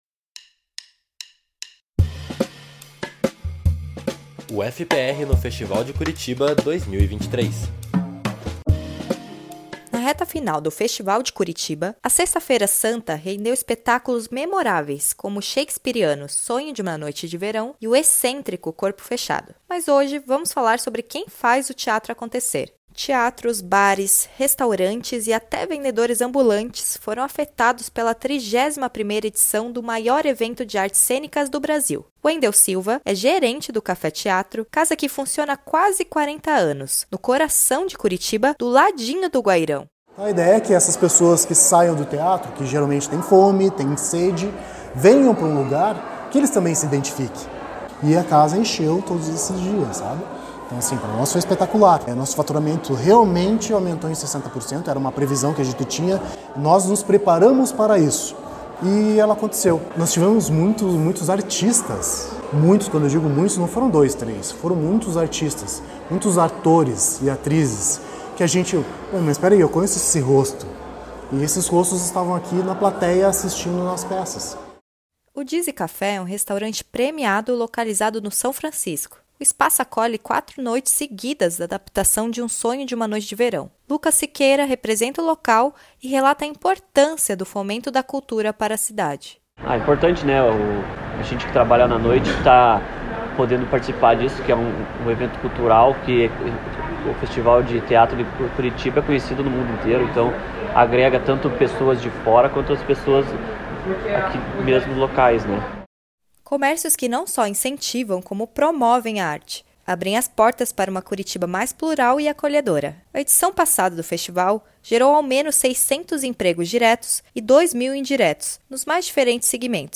A nossa cobertura especial do Festival de Curitiba vai aos estabelecimentos da cidade bater um papo sobre a importância do fomento à cultura. Confira a reportagem